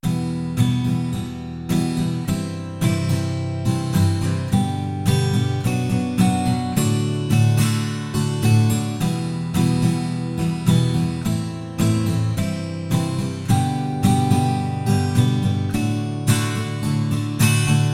原声吉他弦乐
描述：8个无缝小节的原声吉他在混响的环境中弹奏。
Tag: 107 bpm Folk Loops Guitar Acoustic Loops 3.02 MB wav Key : G